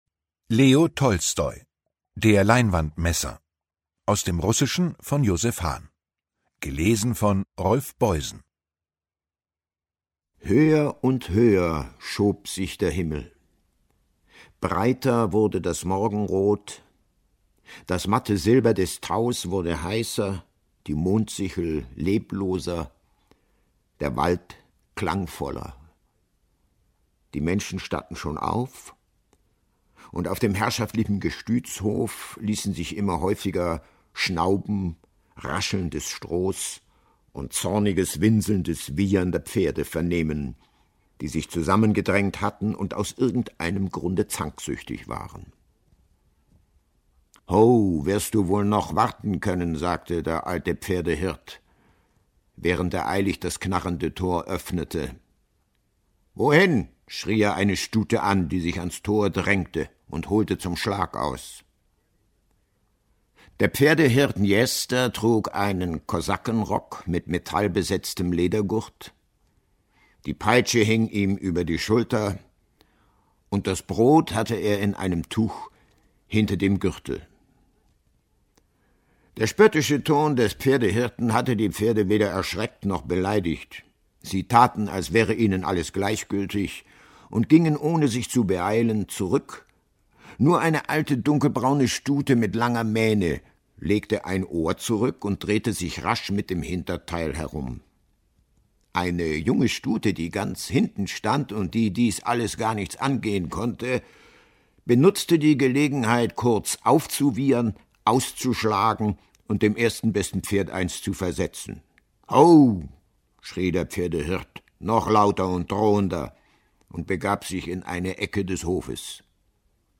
Ungekürzte Lesung mit Rolf Boysen (1 mp3-CD)
Rolf Boysen (Sprecher)